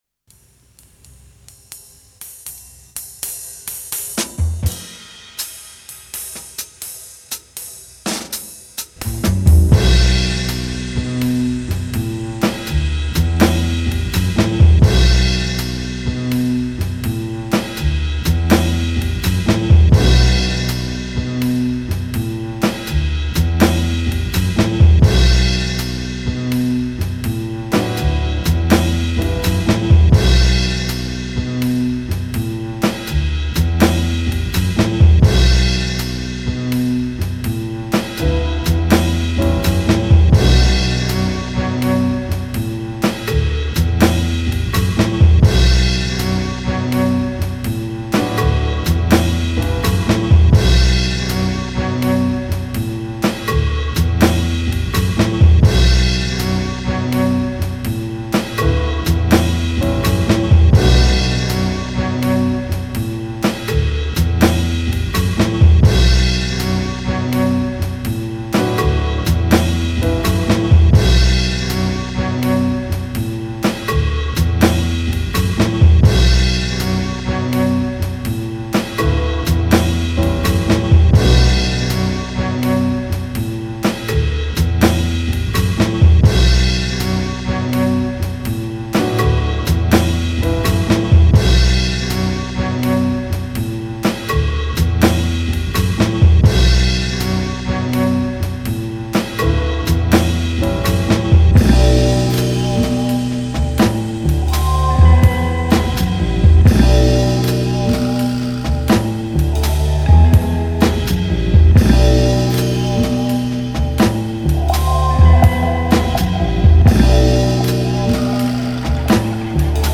est en même temps apaisé et tendu
débute doucement pour s’envoler lentement mais sûrement
jazz